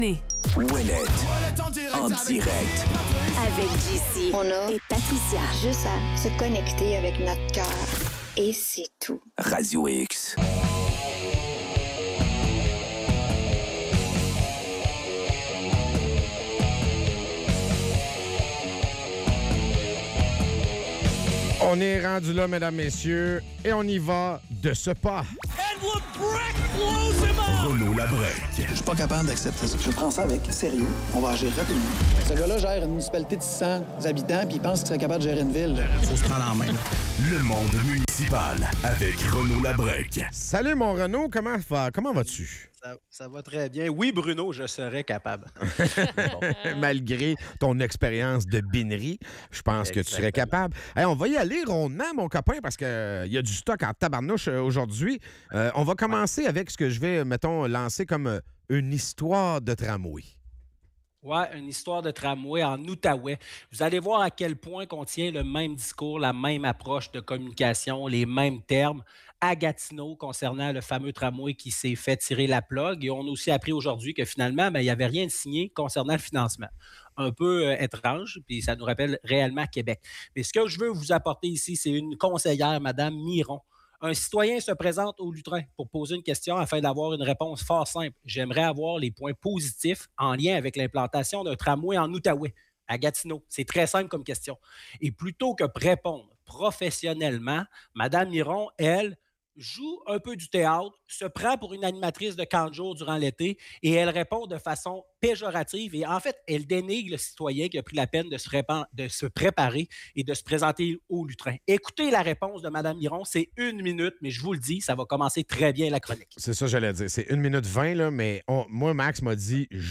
En chronique